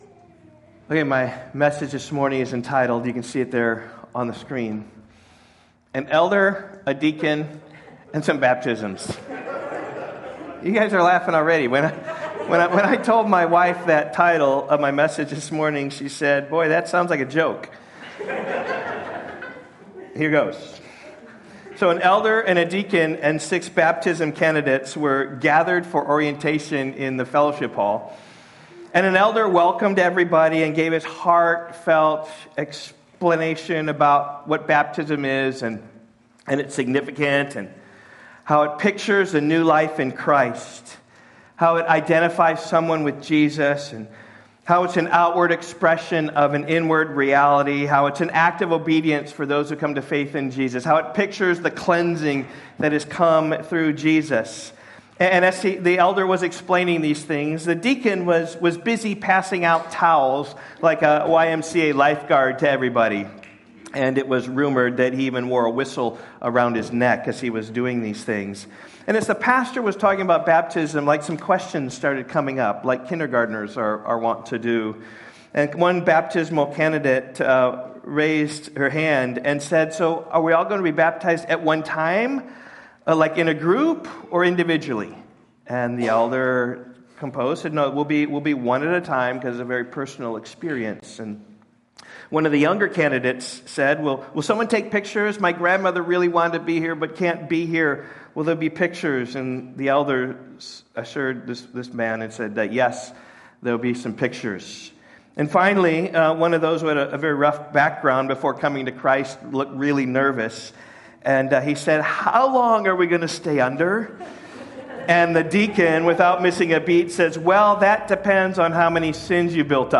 Sermon audio from Rock Valley Bible Church.